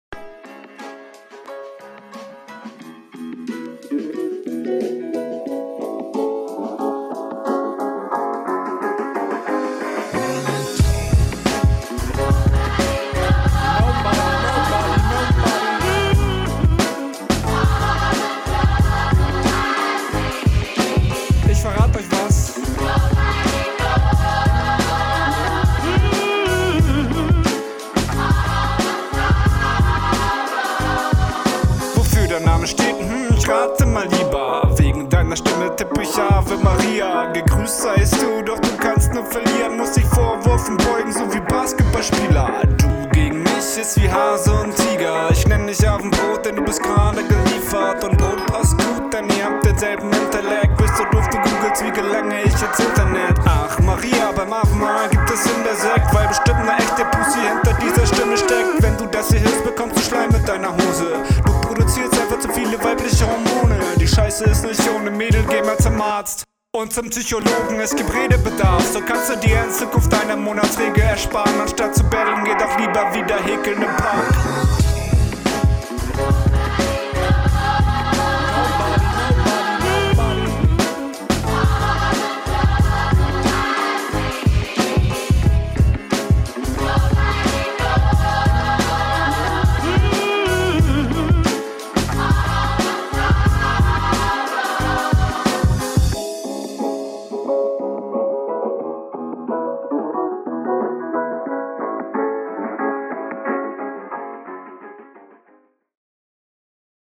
Der Flow passt richtig gut zum Beat und verbreitet eine gute Stimmung.
Hier geht deine Abmische leider auf dem Beat etwas unter. der Beat ist zu laut …